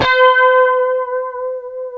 C#5 HSTRT VB.wav